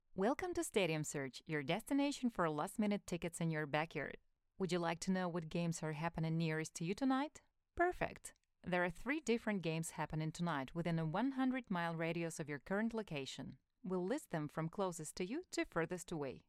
Жен, Другая/Молодой
Конденсаторный микрофон Behringer B-1, звуковая карта Audient Evo 4